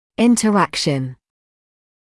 [ˌɪntər’ækʃn][ˌинтер’экшн]взаимодействие